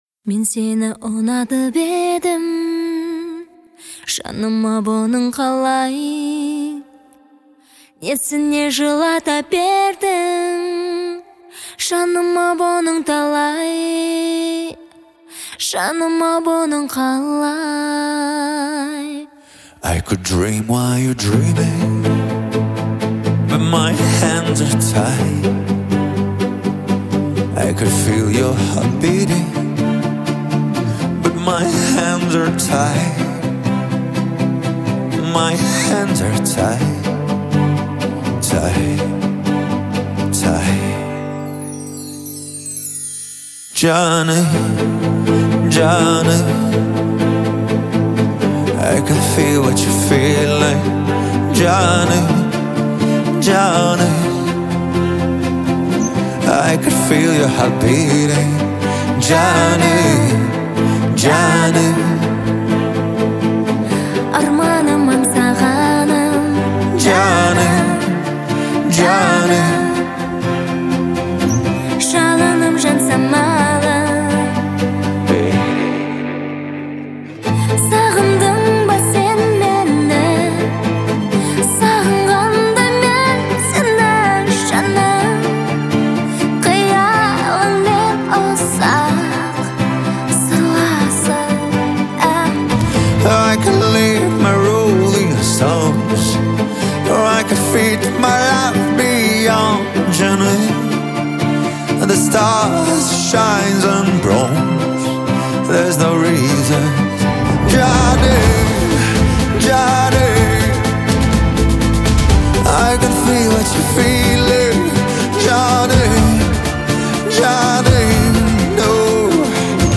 это яркий пример поп-музыки с элементами восточной мелодики.